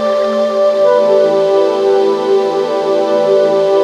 FLUTE 2 SP-R.wav